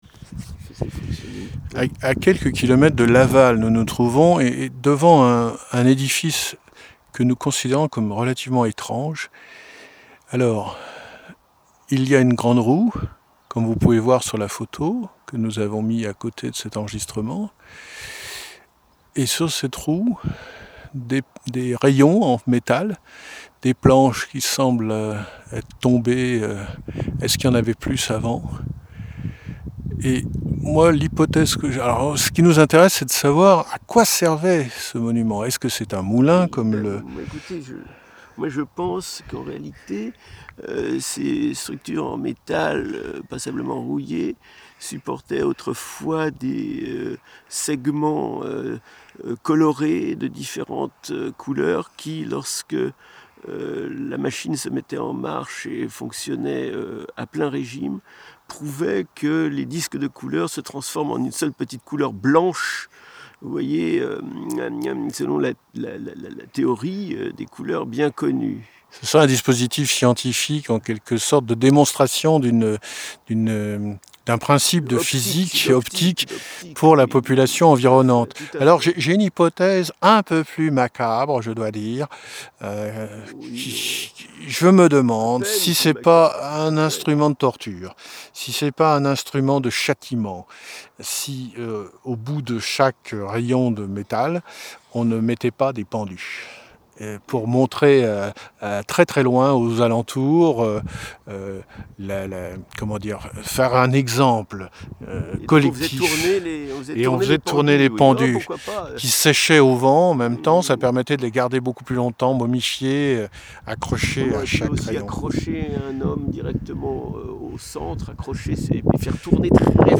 Mais, comme on l'aura compris, ce fut, devant cet édifice, inconnu de nous sur le moment, un beau prétexte à interprétation paranoïa-critique, improvisée de la même manière que celle que nous avions réalisée au cours d'un périple précédent, dans l'Allier, à St-Pardoux, voir note plus ancienne, le 12 mars dernier.